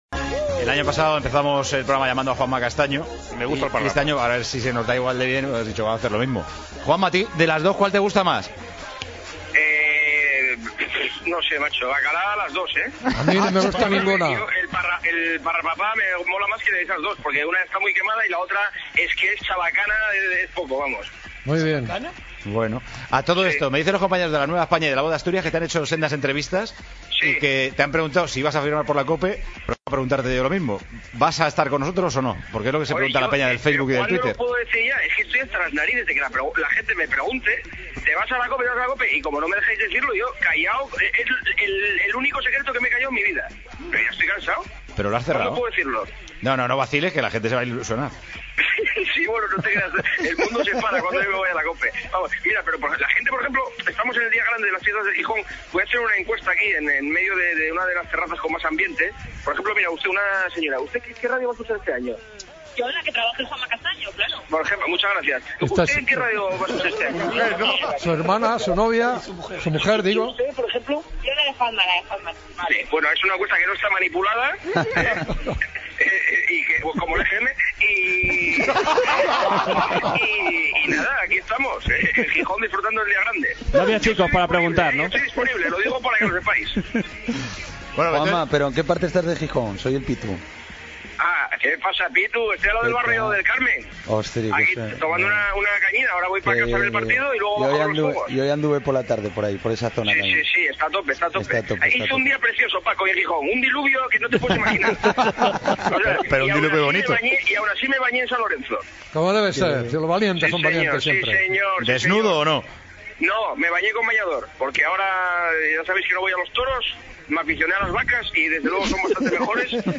Covesa telefònica amb el periodista Juanma Castaño de Canal Plus que està amb la seva família a Xixón sobre si fitxarà per la COPE i com ha passat el dia, indicatiu del programa
Esportiu